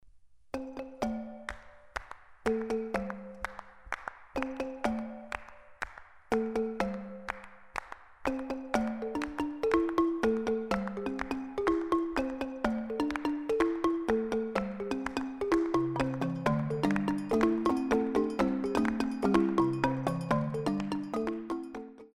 Cd of African rhythms
9 percussion tracks  - some vocals
Based on traditional Gyil melody